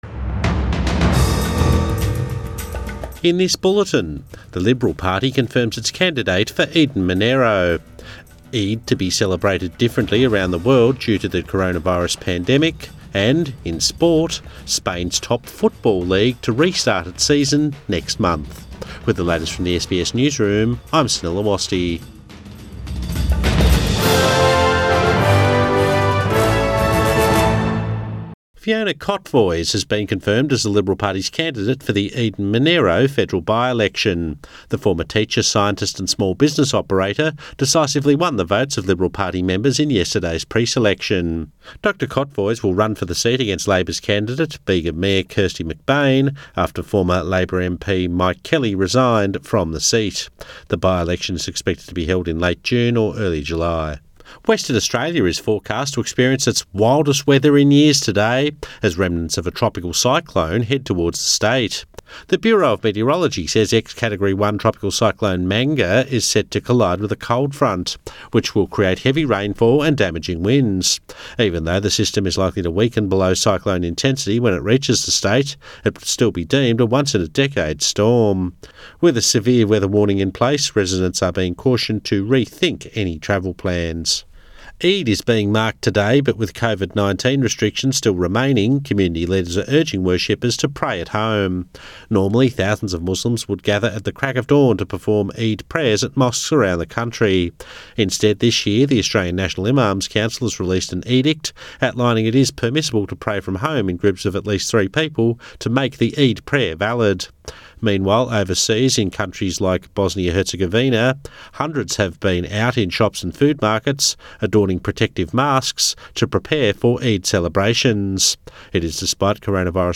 AM Bulletin 24 May 2020